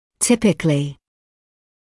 [‘tɪpɪklɪ][‘типикли]типично, обычно